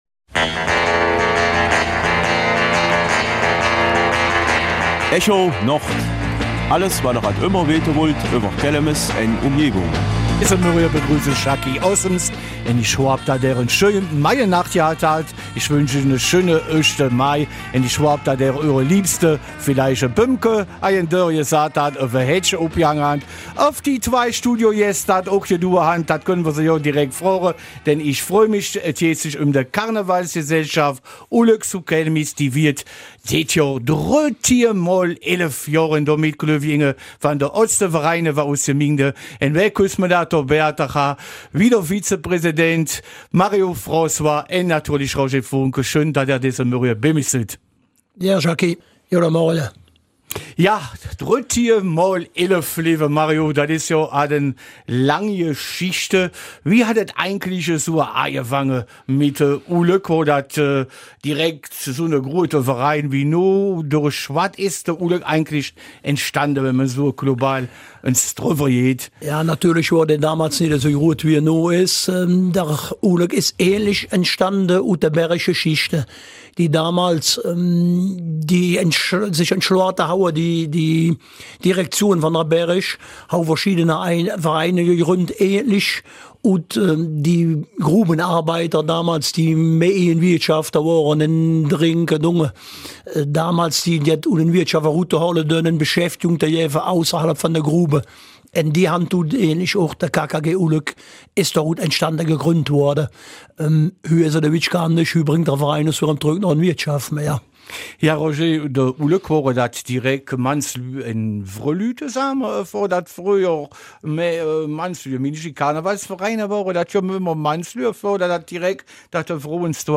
Kelmiser Mundart: 13×11 Jahre KKG Ulk